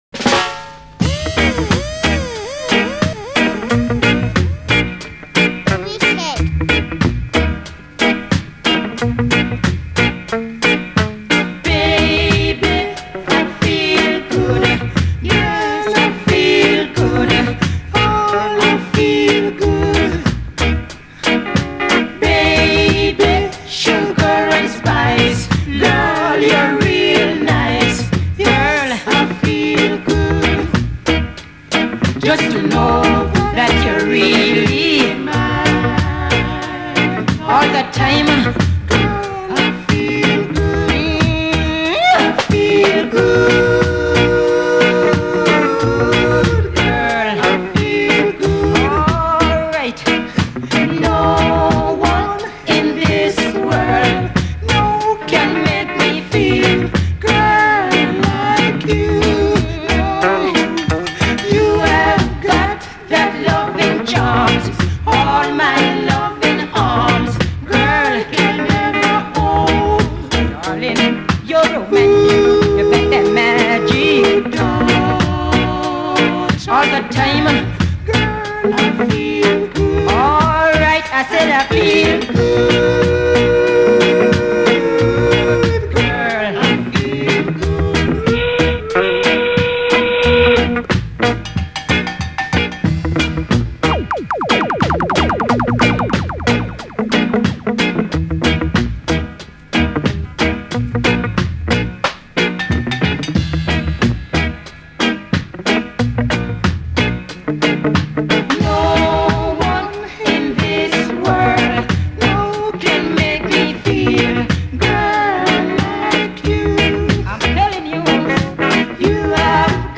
They sound so well, a pure delight…